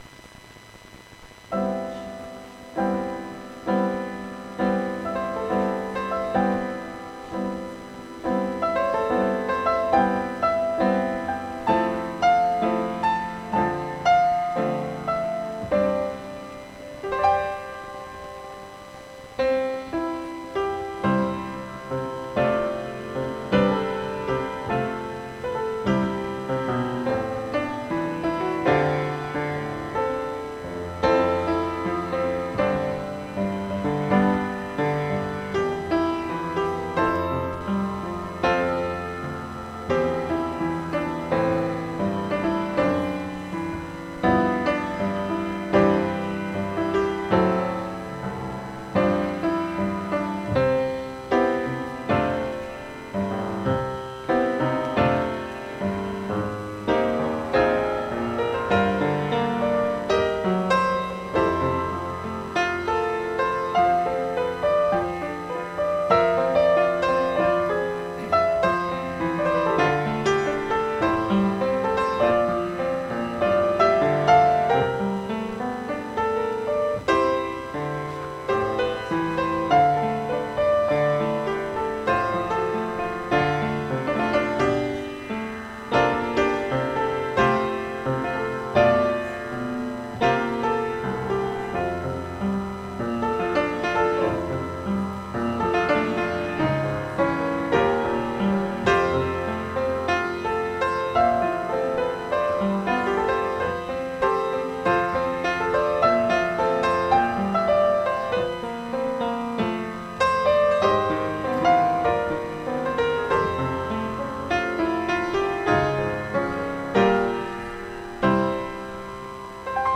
October 1st worship service